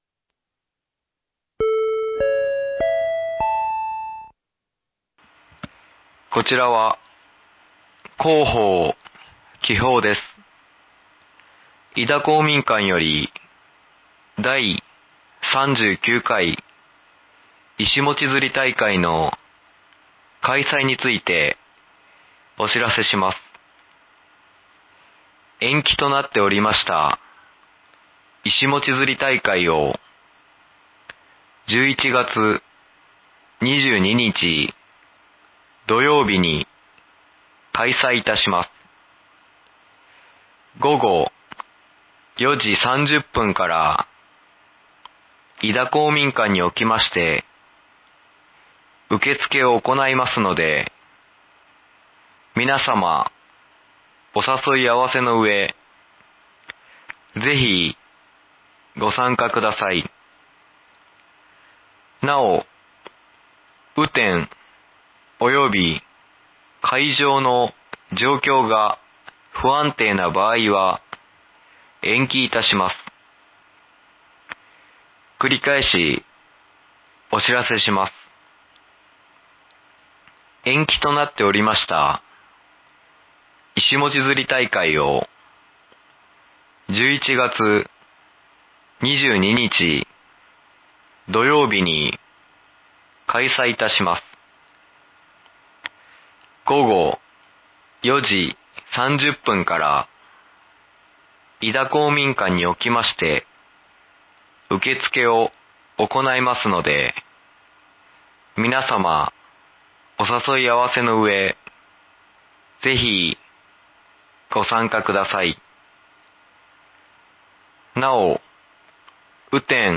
※井田地区のみ放送 延期となっておりました、いしもち釣り大会を、１１月２２日 土曜日に開催いたします。
放送音声